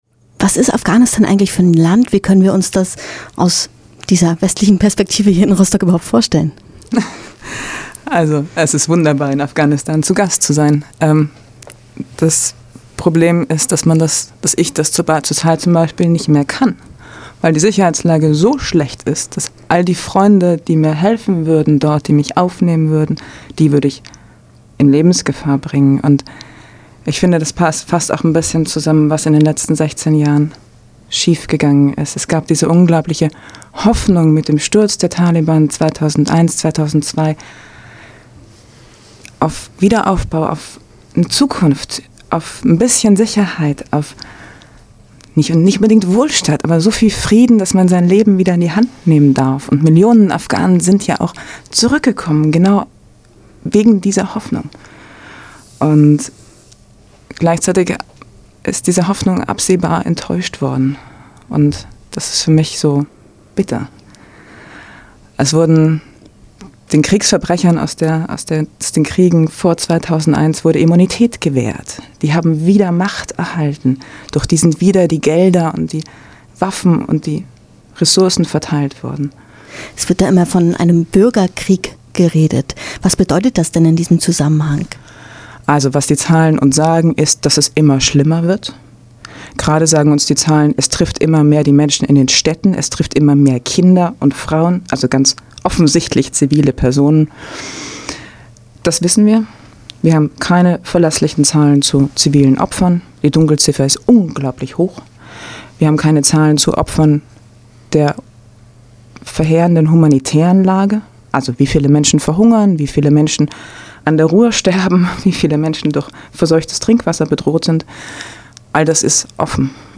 Diese Woche unter anderem: Interview mit Lemur und Landessozialiministerin Stefanie Drese, der Frauentag am 08.